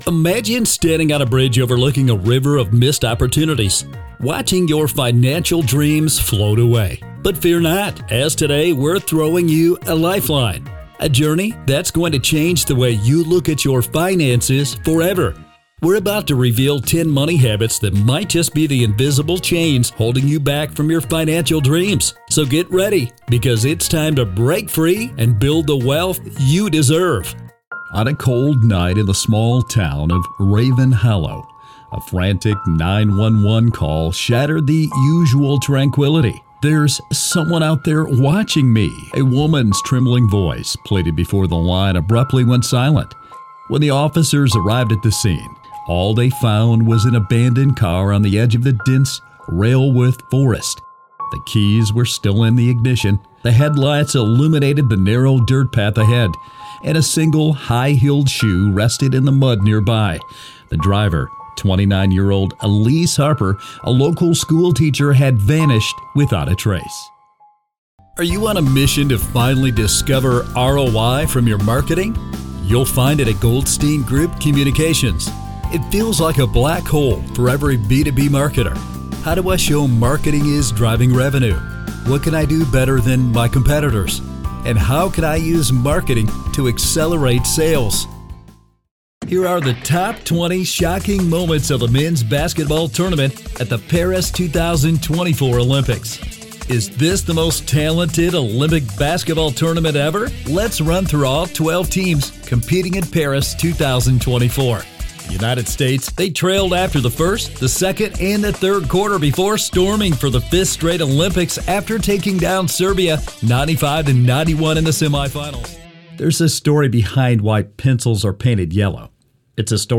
Male, Rich, Deep, Baritone, with 28 Years in Voice Over Experience
Demo Reel 2025 Latest & Greatest
English - USA and Canada
Middle Aged